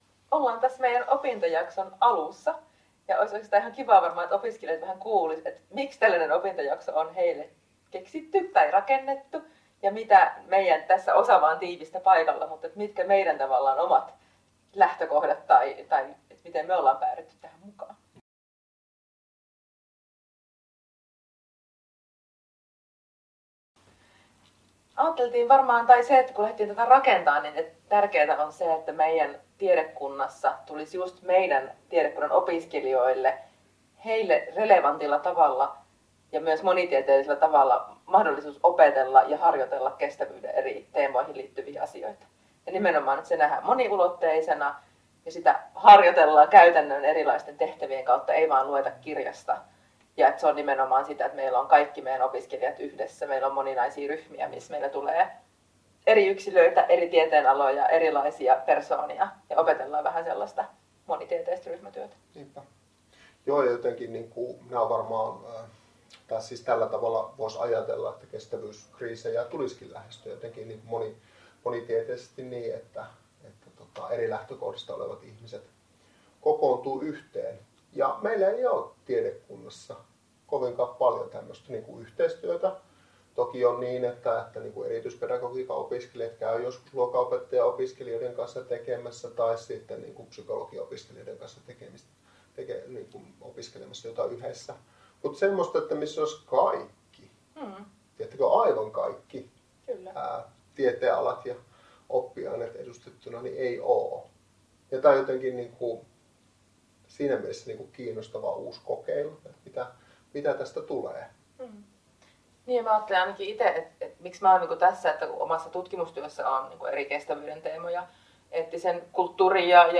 Opettajat avaavat KTPS1000-opintojakson tausta-ajatusta.